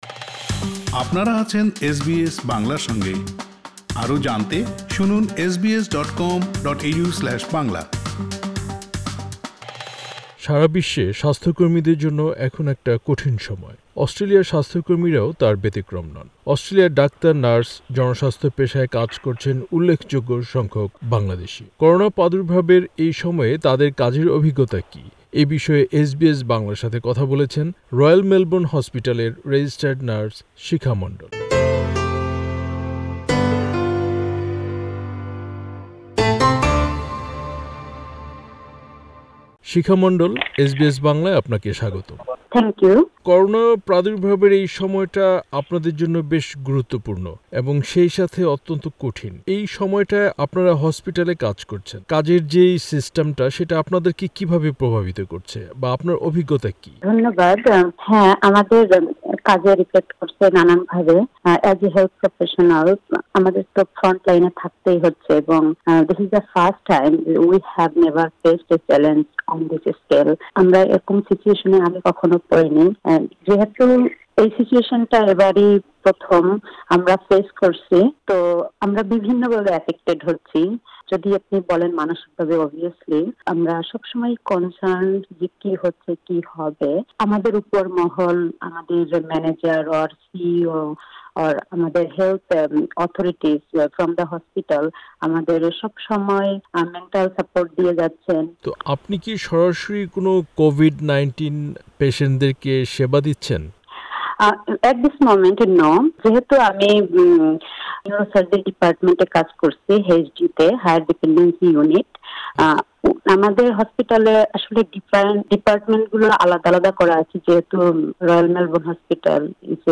করোনা প্রাদুর্ভাবের এই সময়ে তাদের কাজের অভিজ্ঞতা কি? এ বিষয়ে এসবিএস বাংলার সাথে কথা বলেছেন